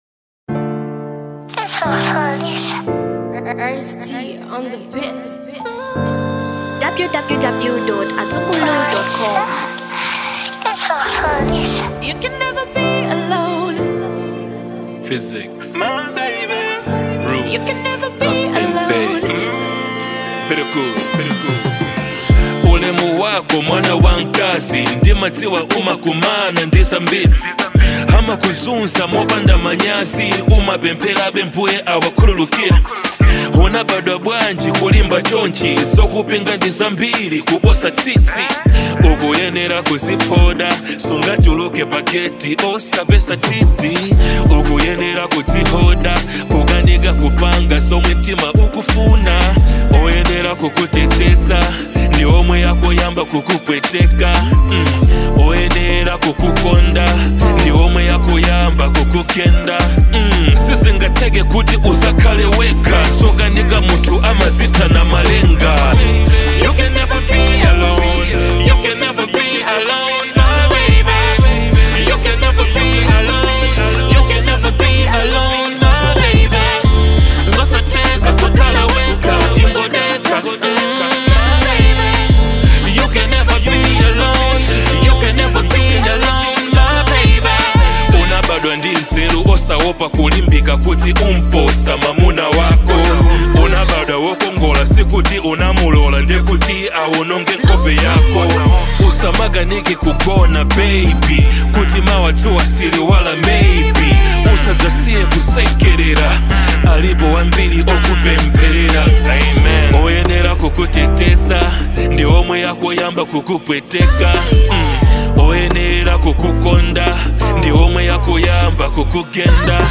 Genre Hip-hop